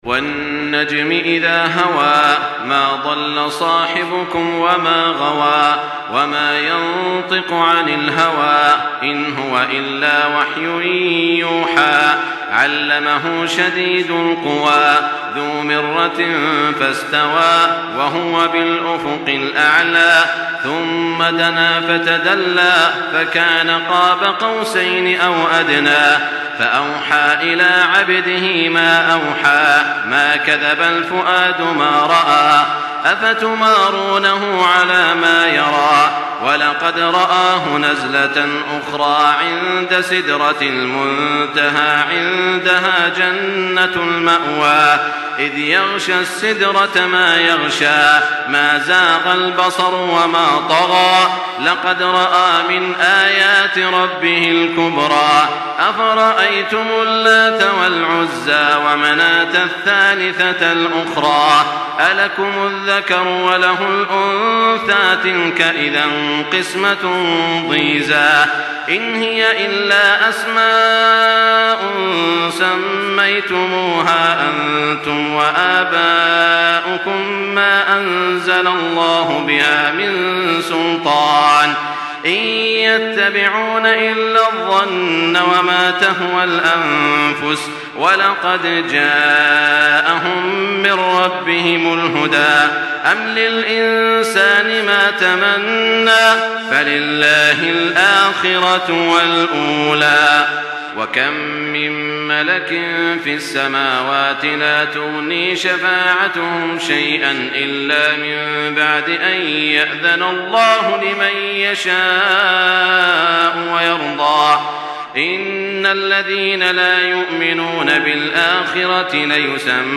Makkah Taraweeh 1424
Murattal